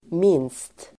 Uttal: [min:st]